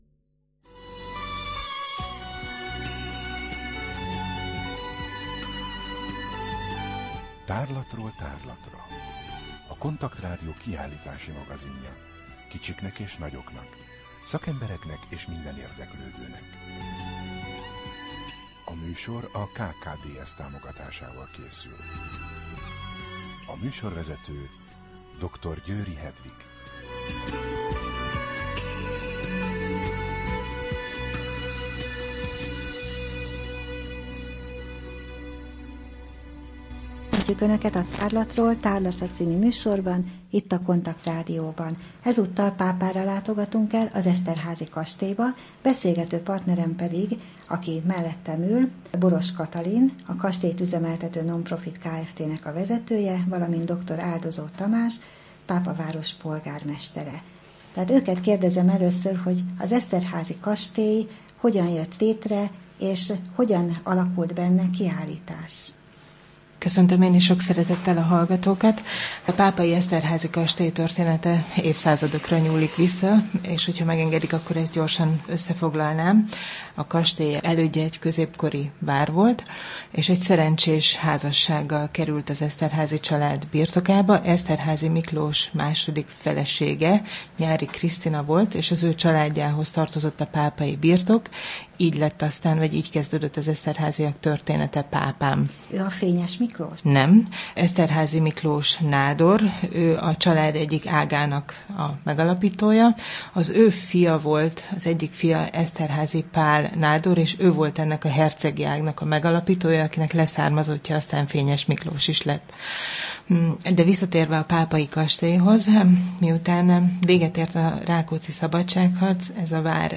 Rádió: Tárlatról tárlatra Adás dátuma: 2015, June 18 Tárlatról tárlatra / KONTAKT Rádió (87,6 MHz) 2015. június 18.
dr. Áldozó Tamás, Pápa polgármestere